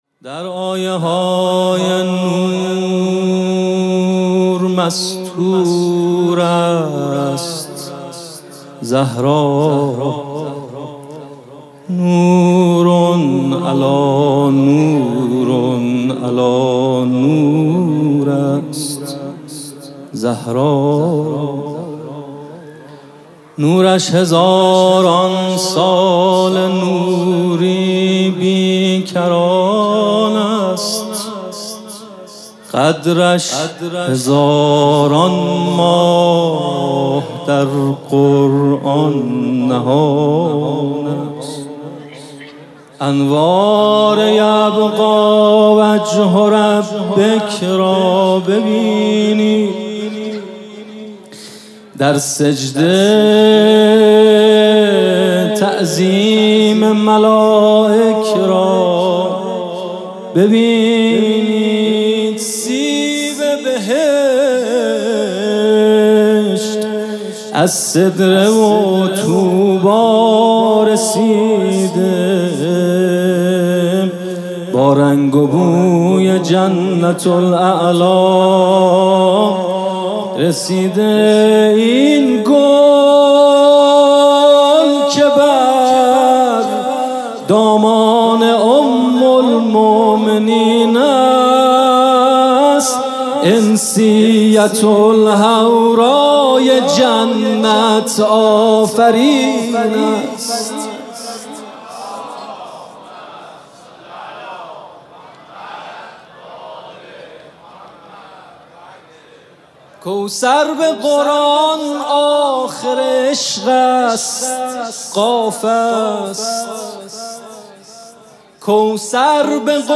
music-icon مدح